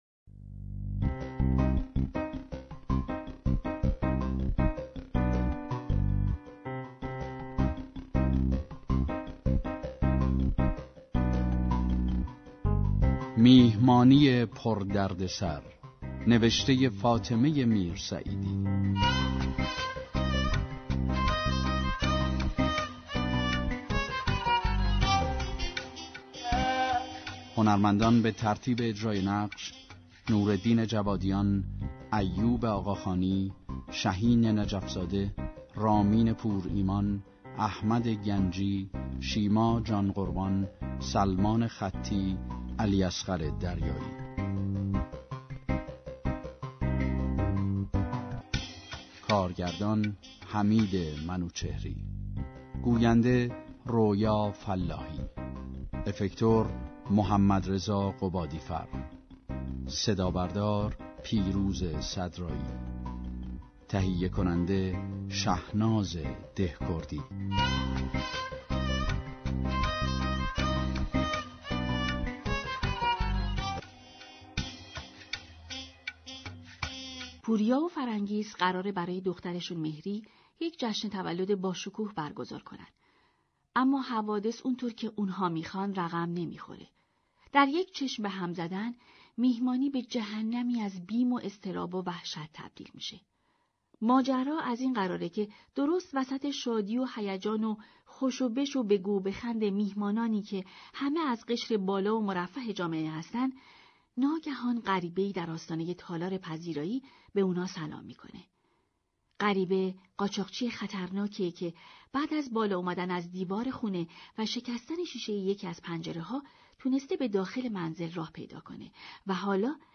پخش یك سریال پلیسی جدید از رادیو نمایش
از روز سه شنبه 12 تیرماه ، پخش سریال جدید پلیسی